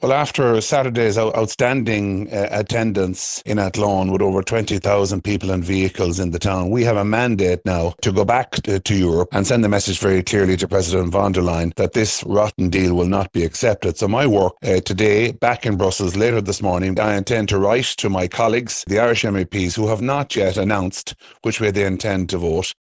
Independent Ireland MEP Ciarán Mullooly says following a weekend demonstration in Athlone, he will write to Irish colleagues asking them to represent Ireland’s interests……….